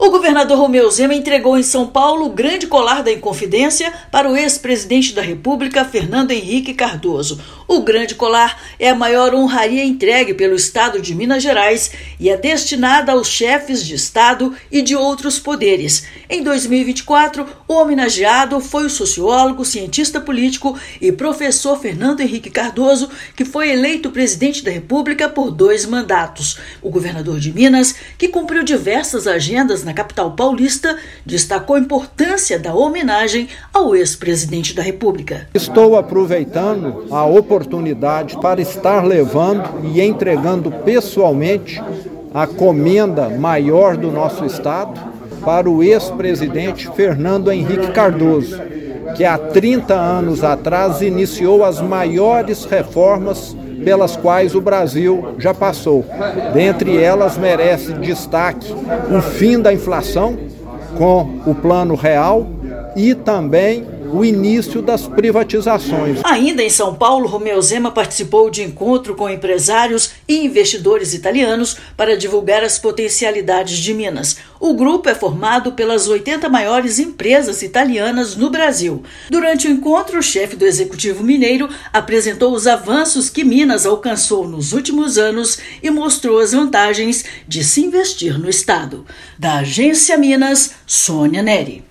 FHC foi contemplado com a maior honraria do Estado de Minas Gerais por sua contribuição para o desenvolvimento do país, com reformas econômicas realizadas, e por ser um dos criadores do Plano Real. Ouça matéria de rádio.